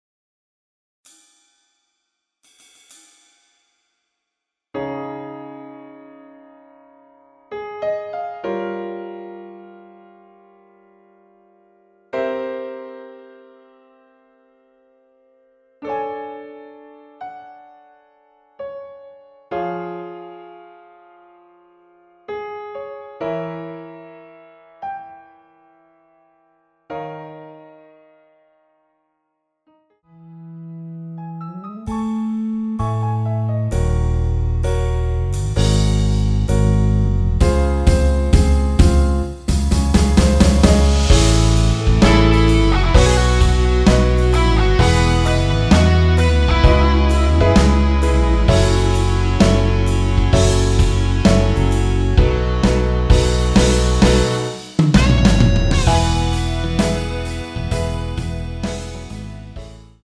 원키에서(-7)내린 MR입니다.
엔딩이 페이드 아웃이라 라이브 하기시 좋게 아래의 가사 까지 하고 엔딩을 만들었습니다.
Db
앞부분30초, 뒷부분30초씩 편집해서 올려 드리고 있습니다.
중간에 음이 끈어지고 다시 나오는 이유는